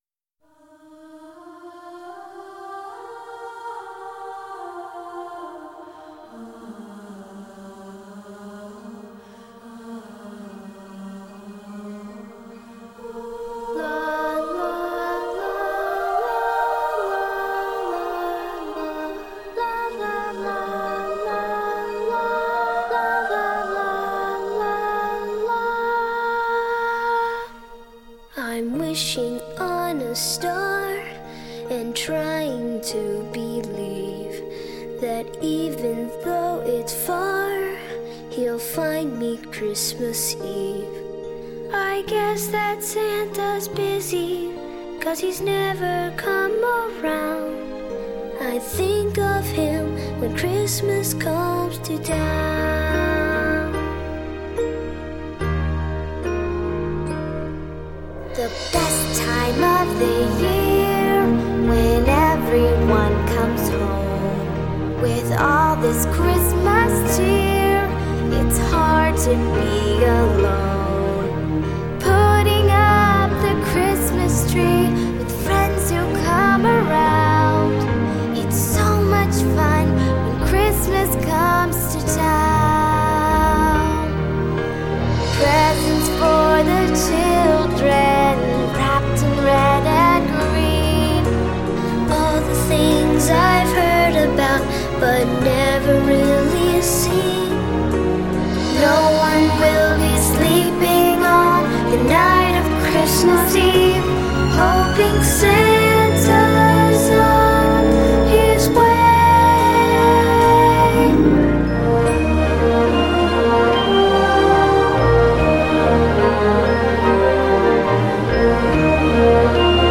★ 音乐类型：O.S.T